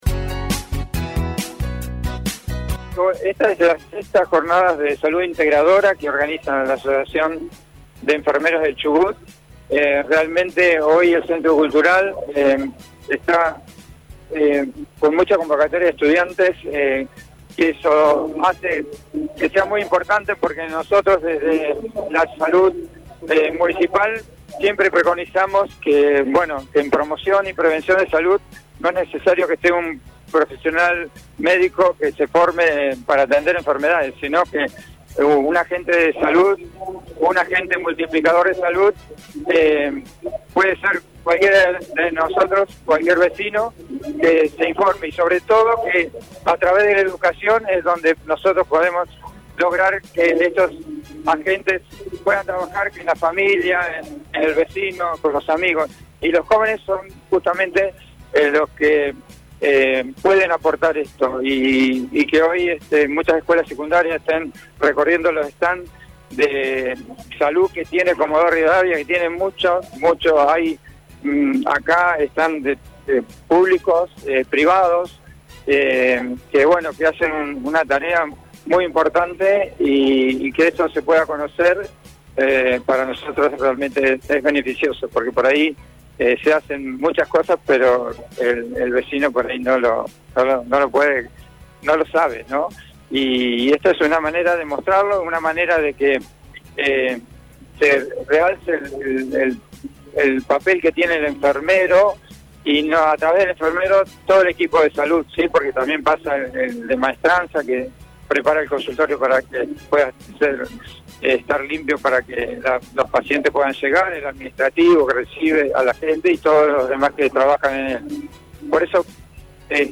Esto decía el secretario al móvil de RADIOVISIÓN: